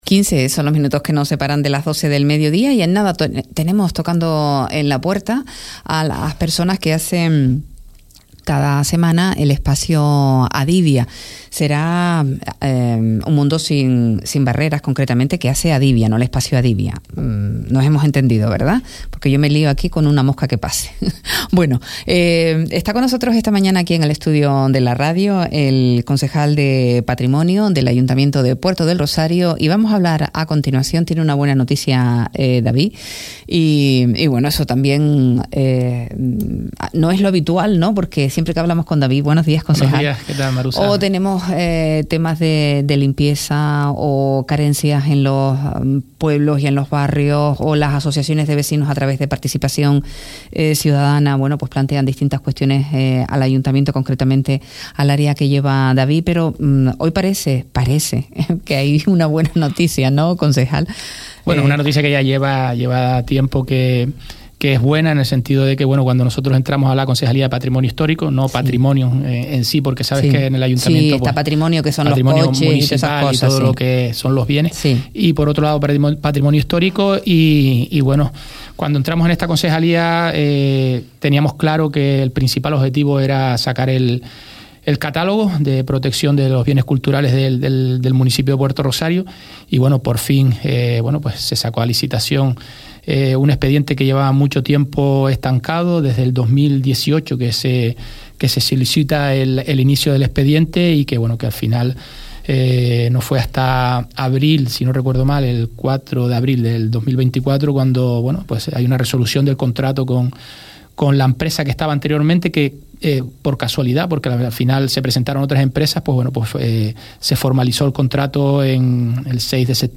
David de León, concejal de Patrimonio Histórico de Puerto del Rosario, se ha sentado esta mañana frente a los micrófonos de Radio Sintonía, en el espacio de Prisma Municipal.